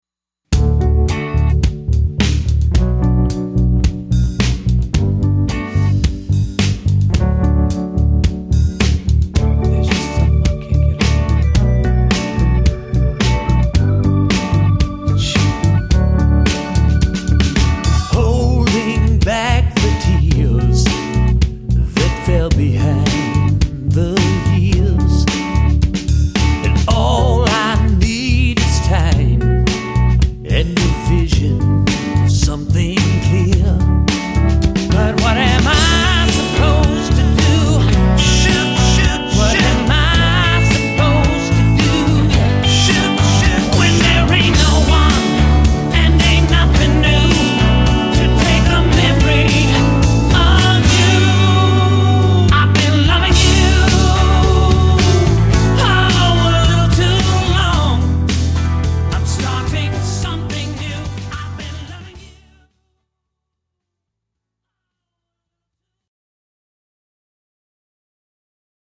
lead vocals and all guitars
drums on all tracks
bass on all tracks
keys, B-3 on all tracks
all strings
backing vocals